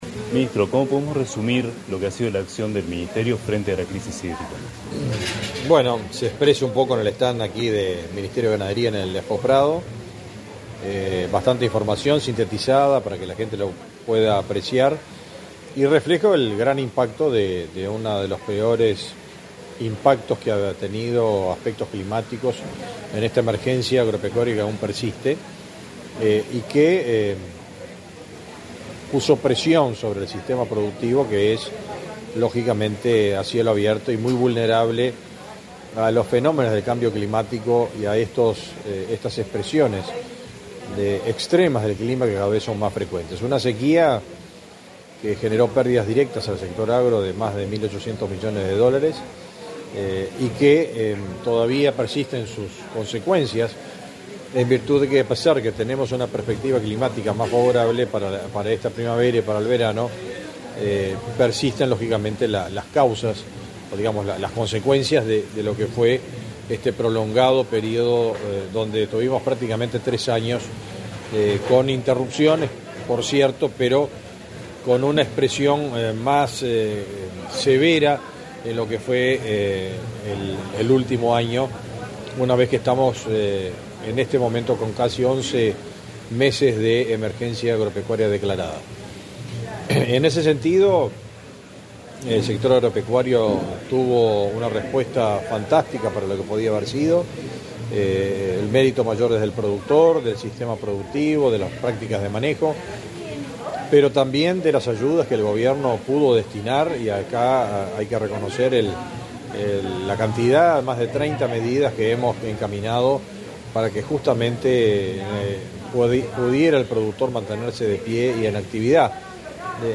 Declaraciones a la prensa de Ganadería, Fernando Mattos
Declaraciones a la prensa de Ganadería, Fernando Mattos 08/09/2023 Compartir Facebook X Copiar enlace WhatsApp LinkedIn Tras participar en la apertura del stand del Ministerio de Ganadería, Agricultura y Pesca (MGAP) en la Expo Prado 2023, este 8 de setiembre, el ministro Fernando Mattos realizó declaraciones a la prensa.